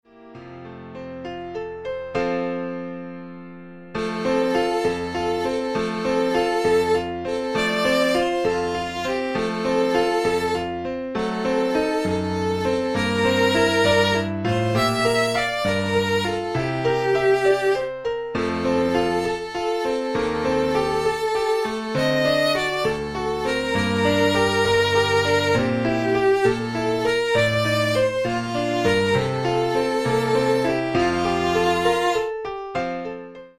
Sample from the Rehearsal MP3